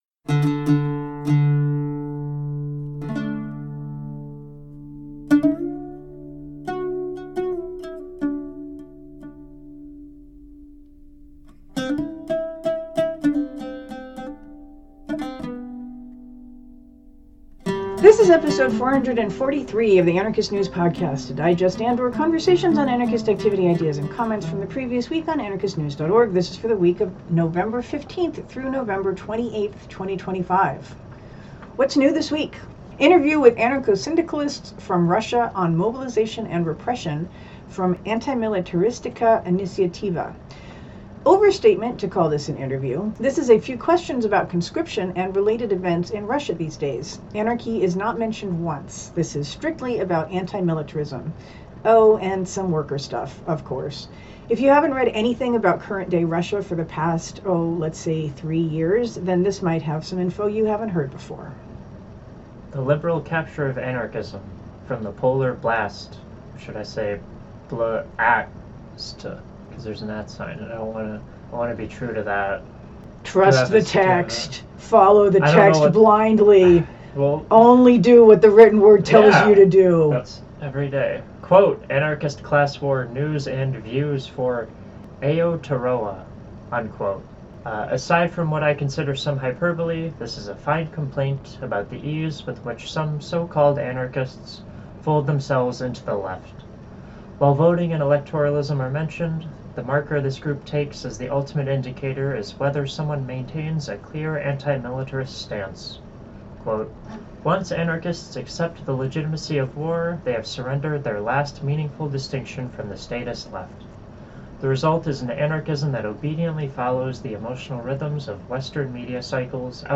music samples